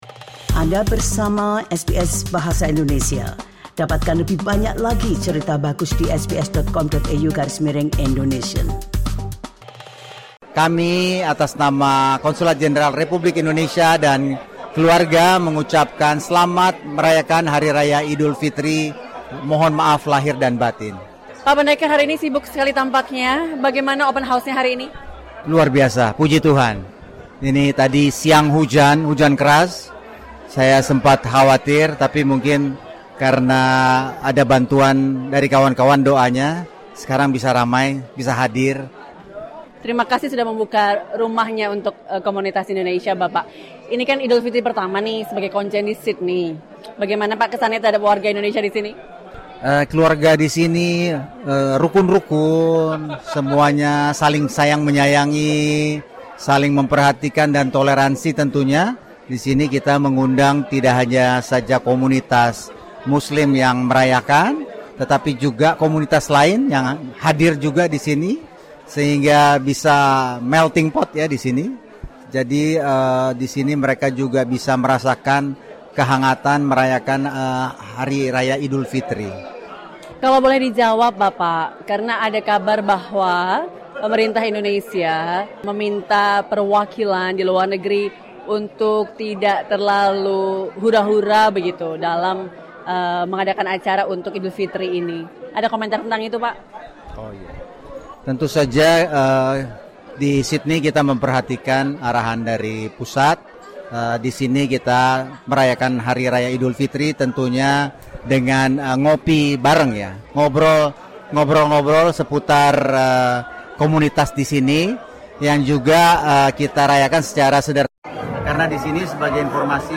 He shared his views on why such events are important for the diverse Indonesian community in Sydney.